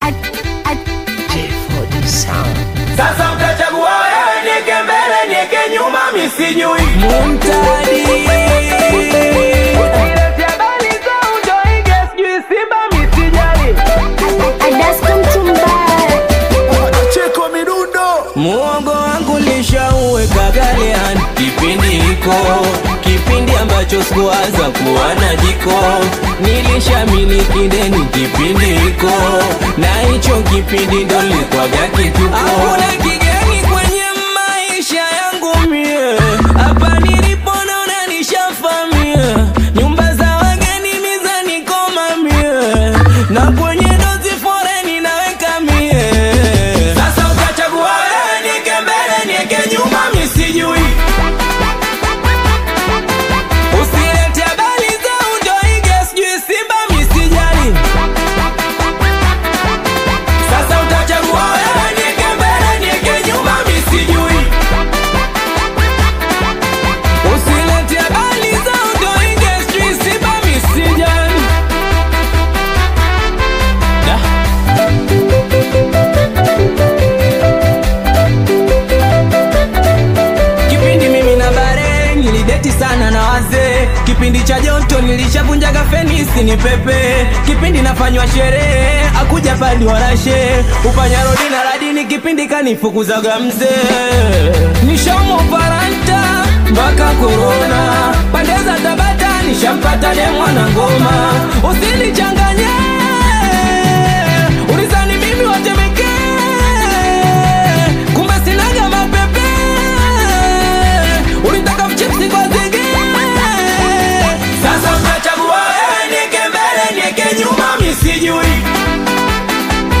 Tanzanian Bongo Flava singeli
singeli song